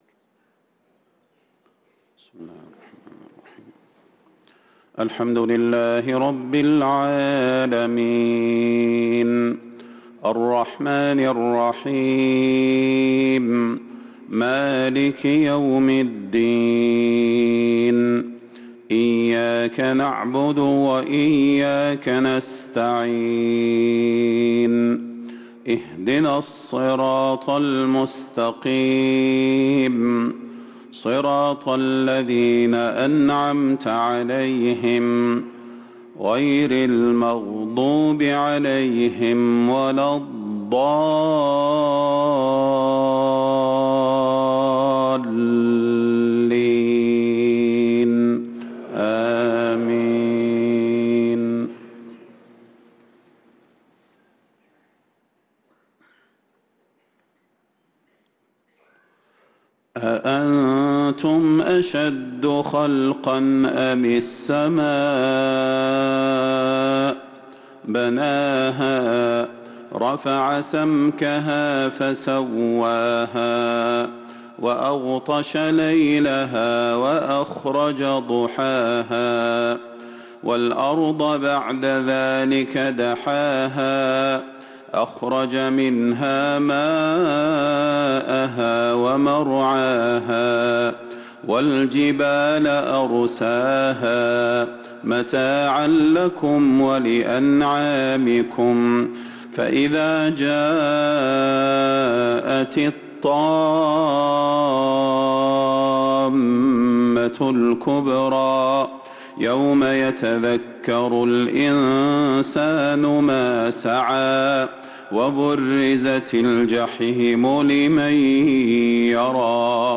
صلاة العشاء للقارئ صلاح البدير 1 جمادي الآخر 1445 هـ
تِلَاوَات الْحَرَمَيْن .